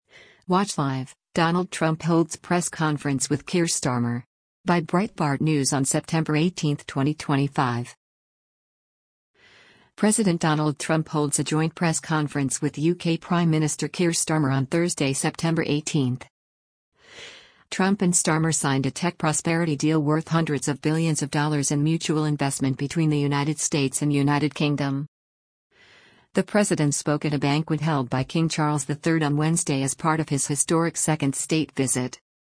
President Donald Trump holds a joint press conference with U.K. Prime Minister Keir Starmer on Thursday, September 18.